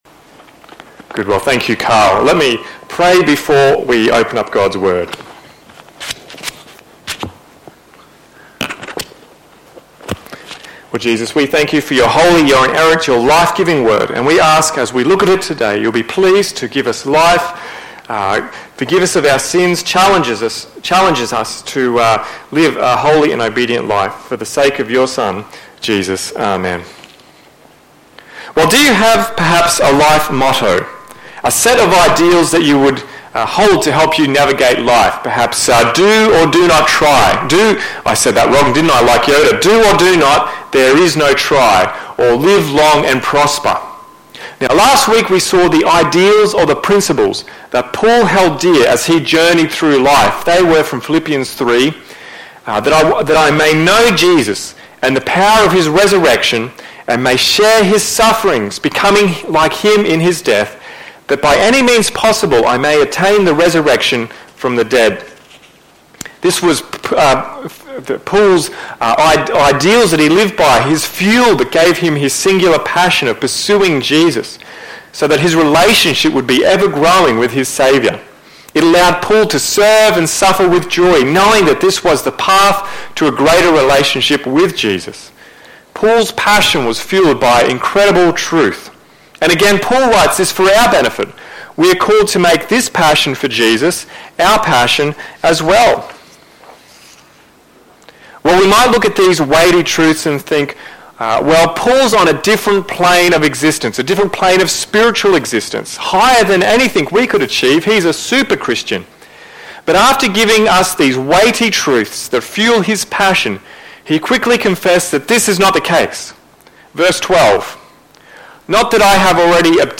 Sermons | Mount Isa Baptist Church